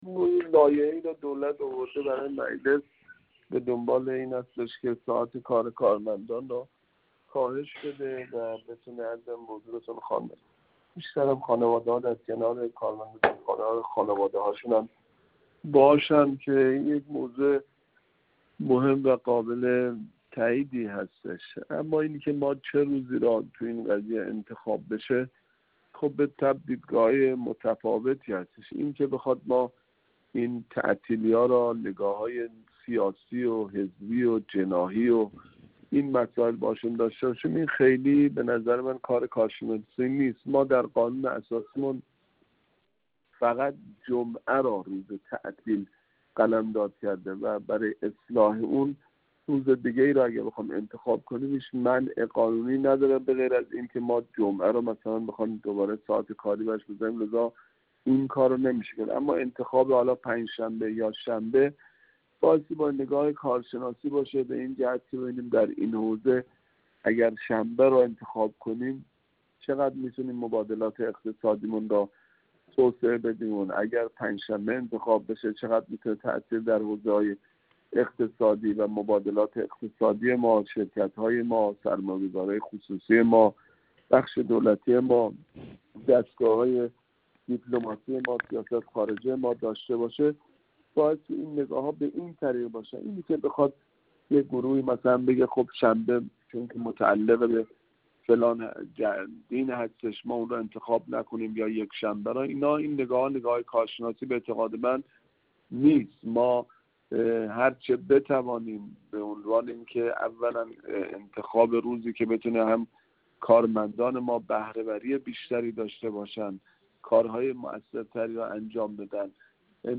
محمدحسن آصفری، عضو کمیسیون امور داخلی و شوراهای مجلس شورای اسلامی
گفت‌وگو